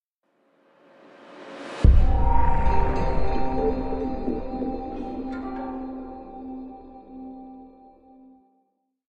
Звук завершения времени